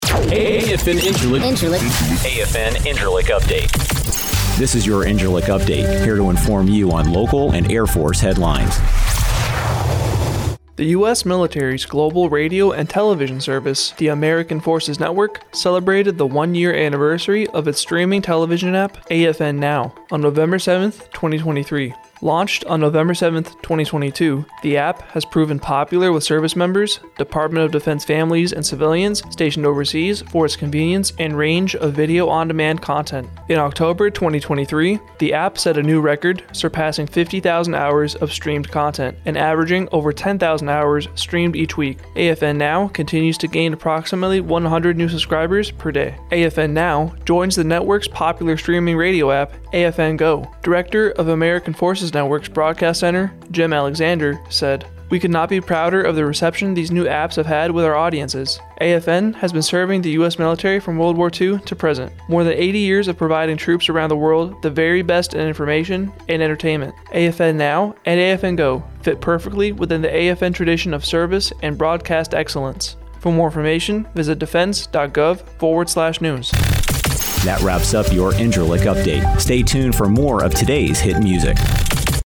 AFN INCIRLIK RADIO NEWSCAST: AFN Celebrates One Year of AFN Now